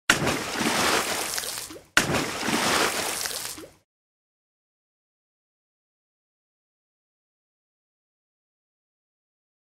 Zil sesi Su Sıçratma
Kategori Ses Efektleri
Su-Sıçratma.mp3